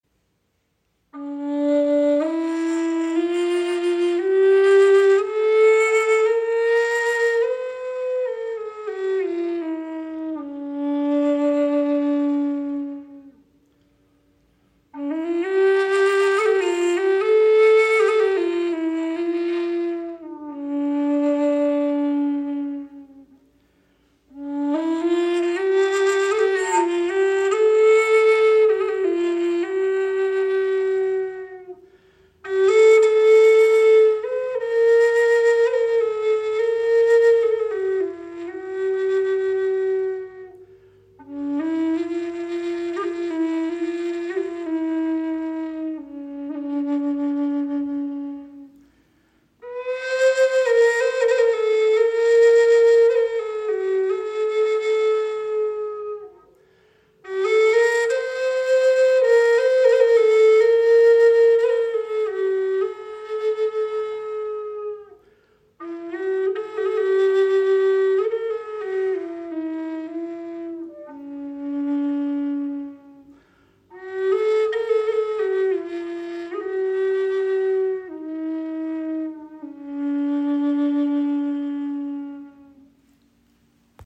Gebetsflöte in D - Aeolian im Raven-Spirit WebShop • Raven Spirit
Klangbeispiel
Dies ist eine wundervolle Gebetsflöte, die auf D Moll in der Stimmung Aeolian gestimmt ist.